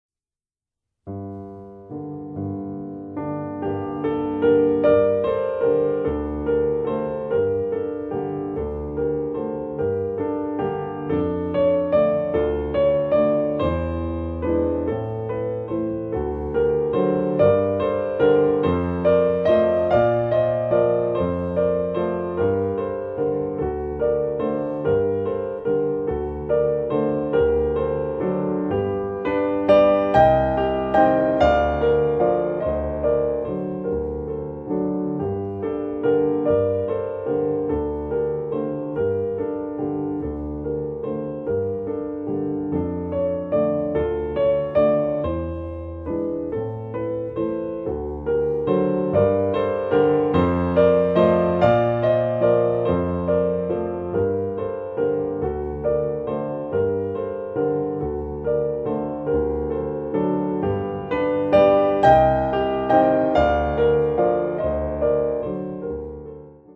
zur vorgegebenen Liedbegleitung zu singen